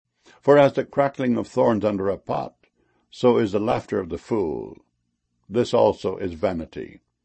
crackling.mp3